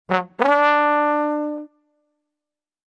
Descarga de Sonidos mp3 Gratis: trompeta comedia 13.